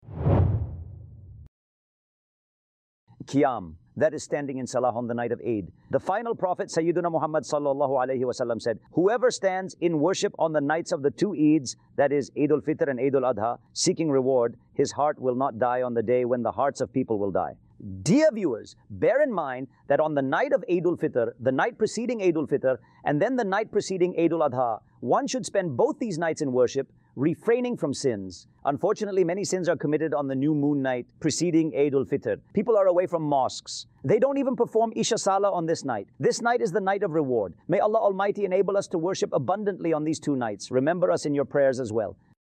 khutba